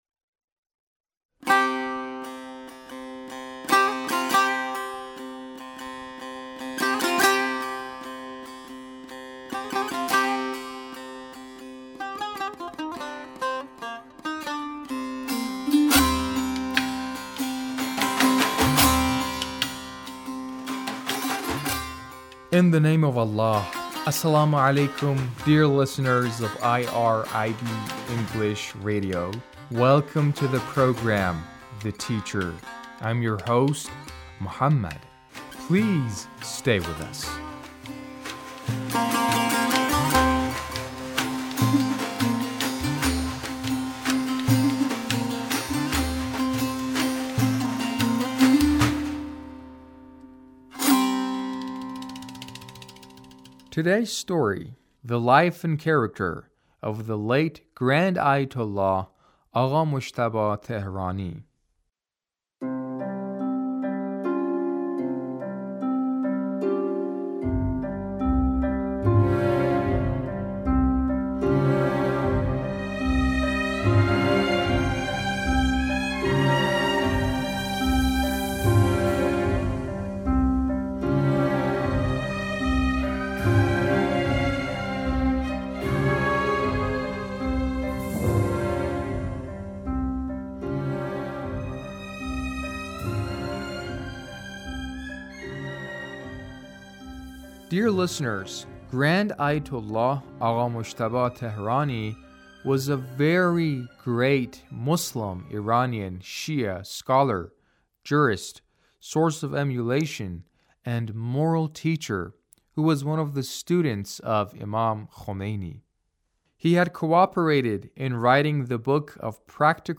A radio documentary on the life of Ayatullah Agha Mojtaba Tehrani